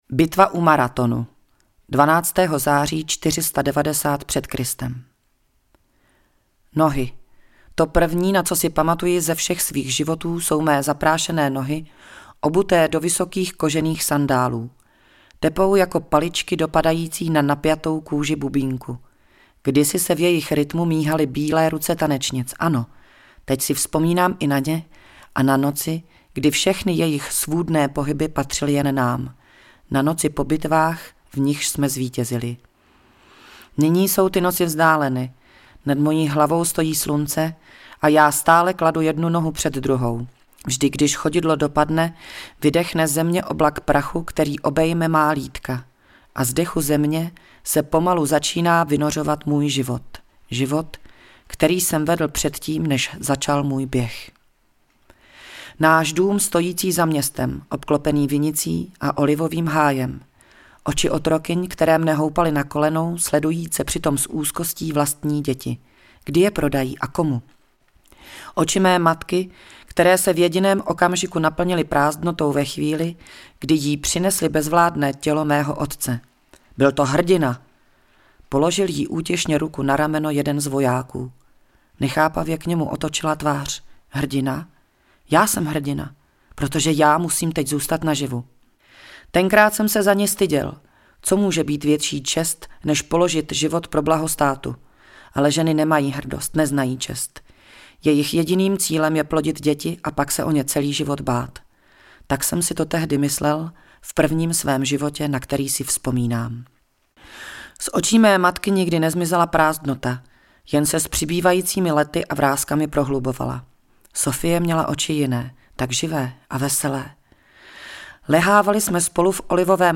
Audiokniha Rodiny a rodinky, autoři Alena Mornštajnová, Miloš Urban, Petra Dvořáková a další.